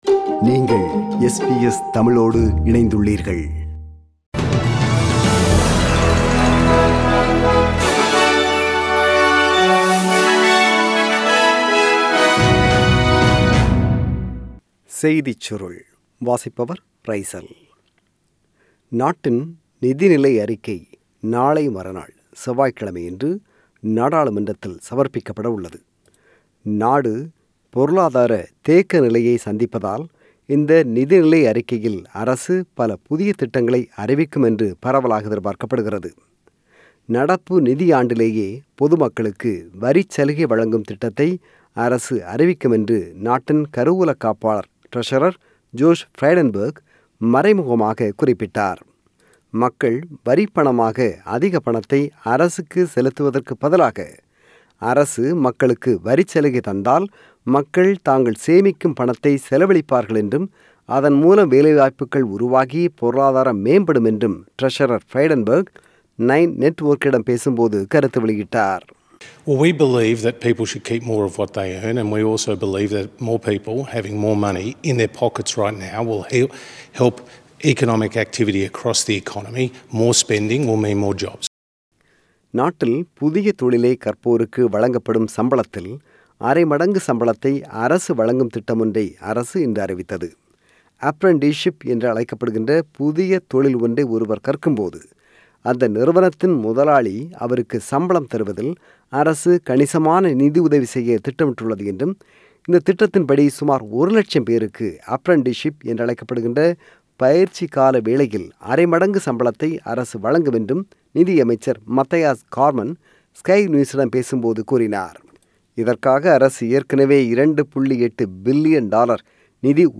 The news bulletin on 4 October 2020 (Sunday) at 8pm.